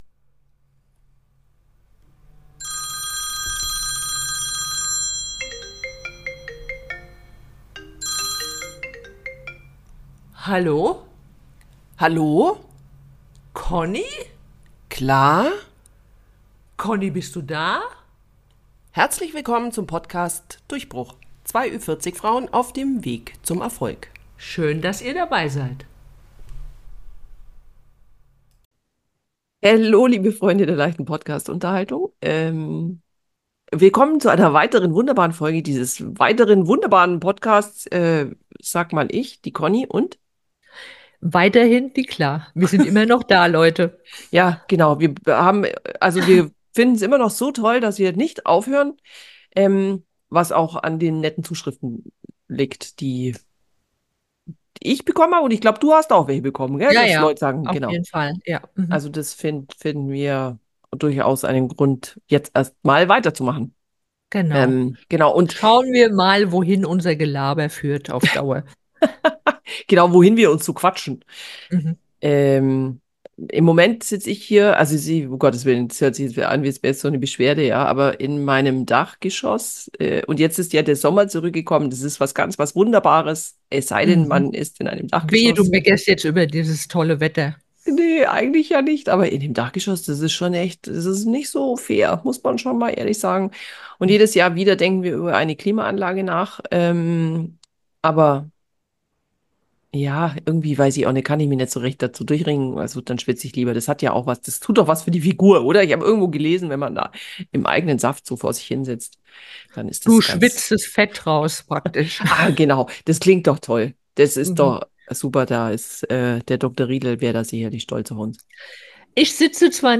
Zwei ü40-Lifestyle-Bloggerinnen tauschen sich aus über alle Themen, die Frauen, dieser Generation beschäftigen.